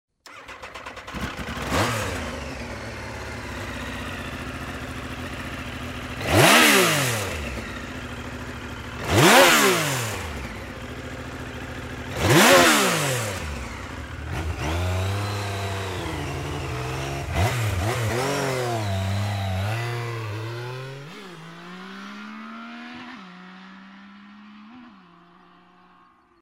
Klicka för att höra Triumphs trecylindriga motor Speed Triple med kolfiber-slipon Klicka för att höra Triumphs trecylindriga motor